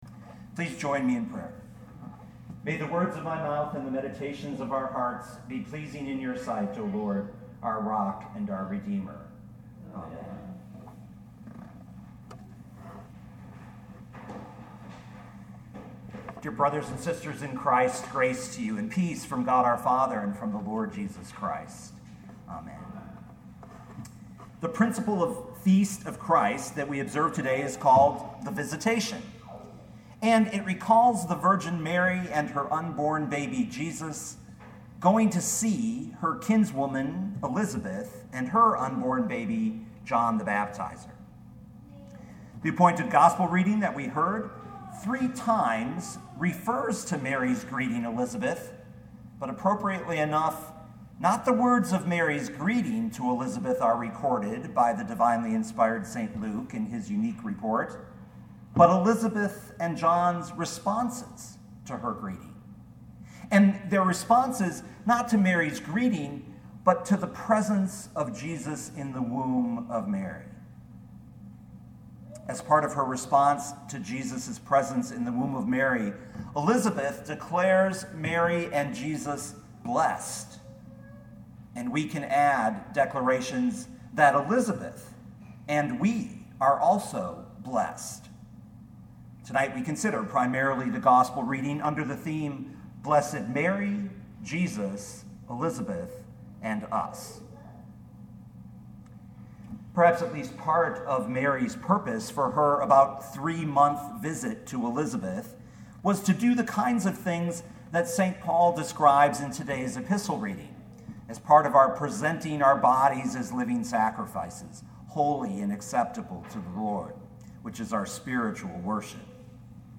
2018 Luke 1:39-45 Listen to the sermon with the player below, or, download the audio.